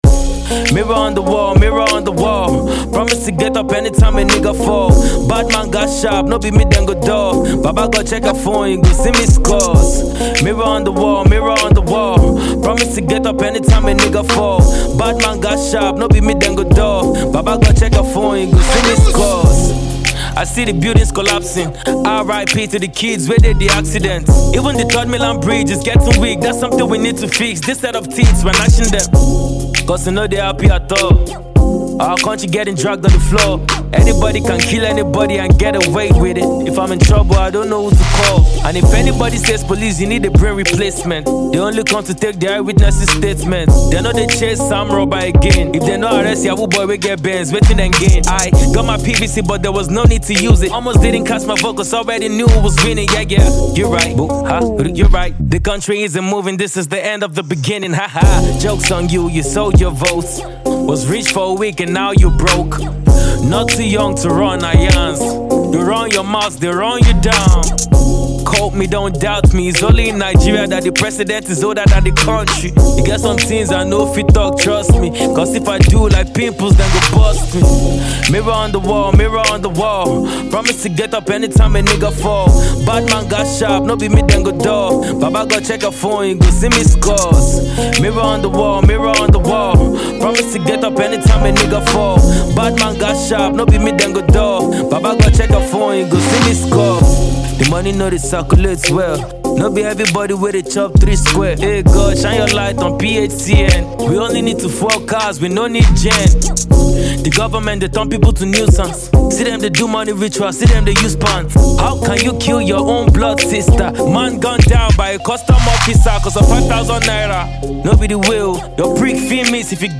Rap artiste
quick freestyle
the rapper shows he still got bars and flows